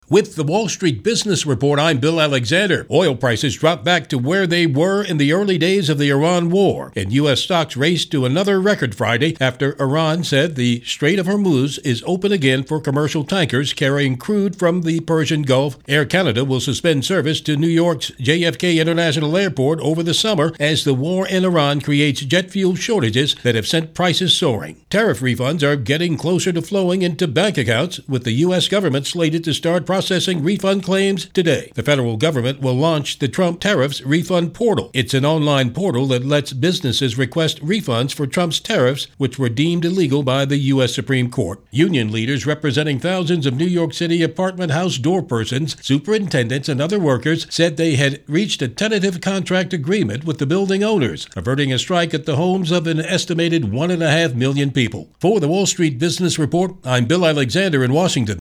News stories as heard on SRN Radio News.